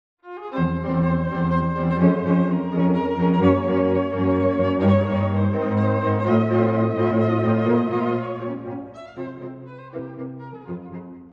↑古い録音のため聴きづらいかもしれません！（以下同様）
1楽章最後の慟哭を受け継いでいるような曲調です。
中間部とコーダでは、疲れたように漂うチェロの音が印象的です。